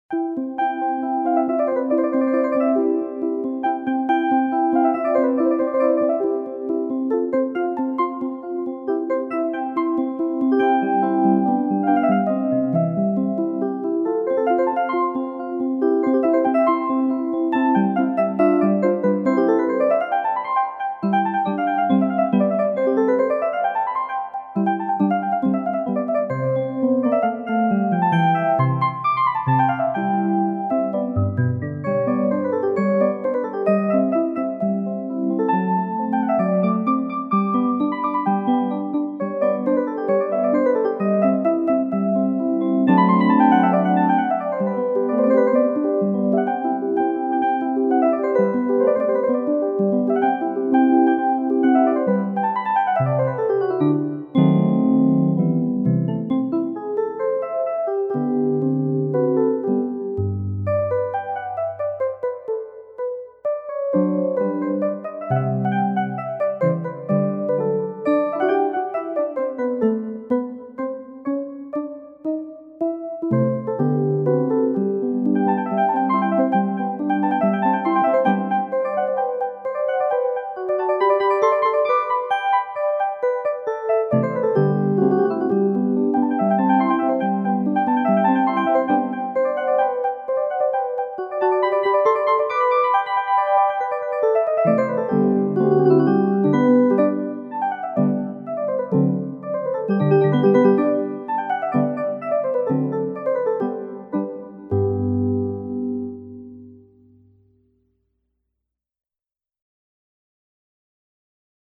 Соната №10 для фортепиано до мажор, К330 - Моцарт - слушать
Классическая музыка величайшего композитора для взрослых и детей.
piano-sonata-in-c-k330.mp3